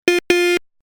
알림음(효과음) + 벨소리
알림음 8_Claxon1.mp3